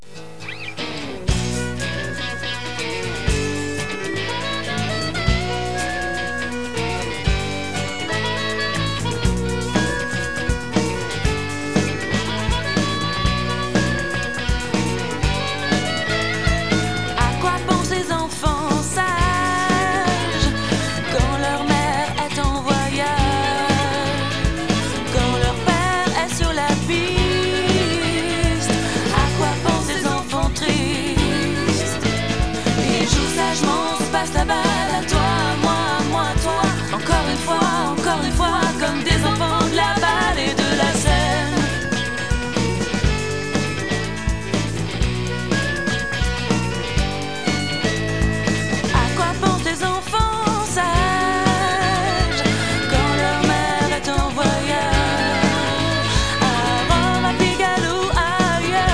Musique Ethnique de la Basse Vallée de l'Ourcq